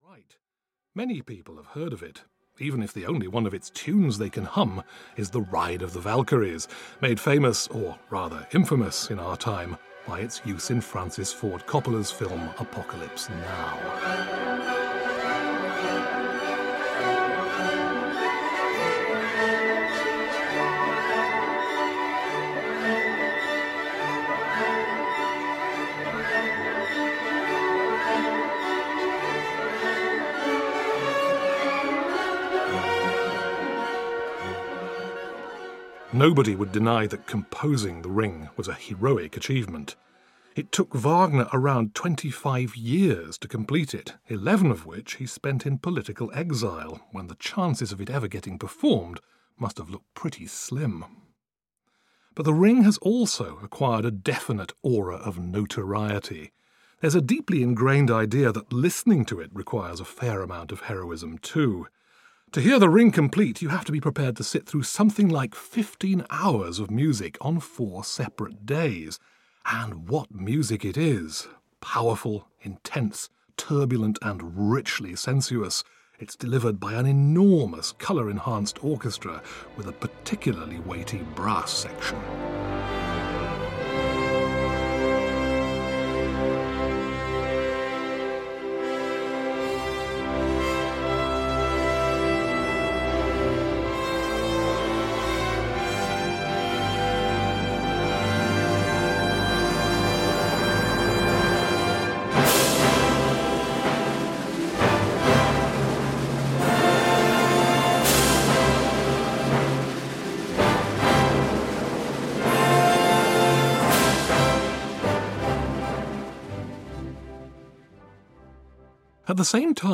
Audio knihaOpera Explained – The Ring of the Nibelung (EN)
Ukázka z knihy